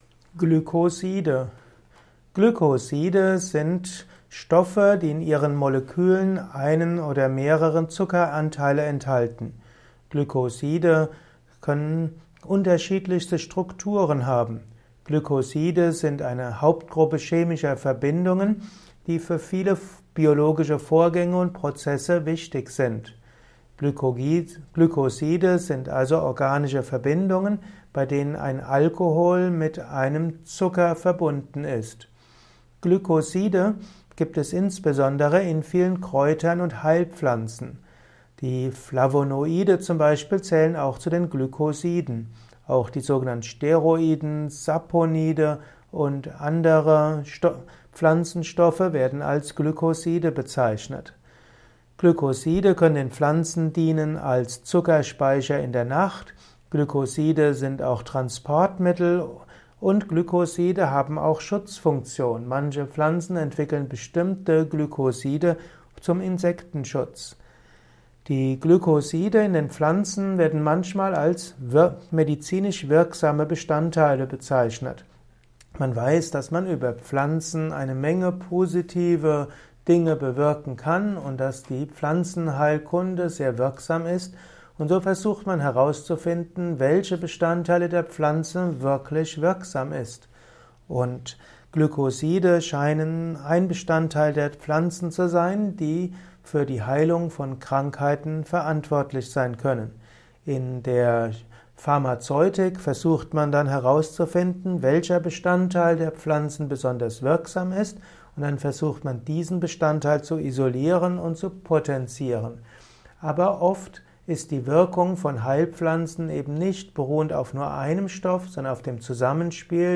Ein Kurzvortrag über den Begriff Glykoside